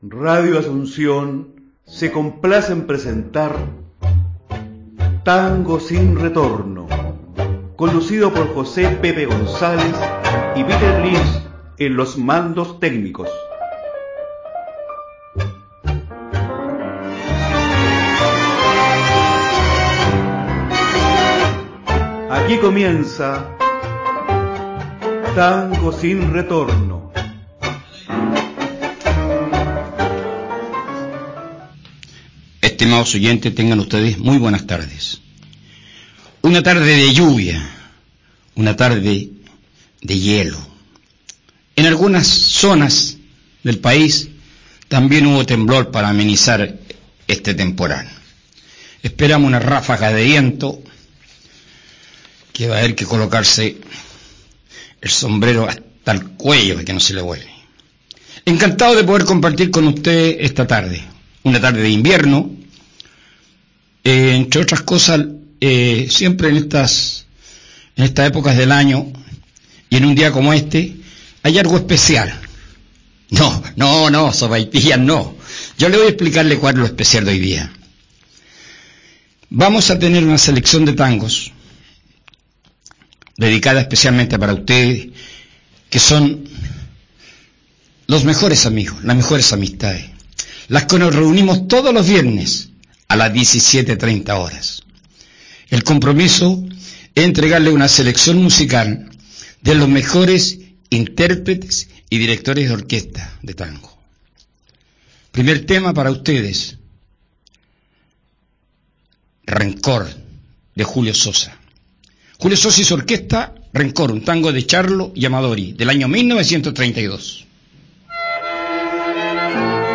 tangos, Boleros y valses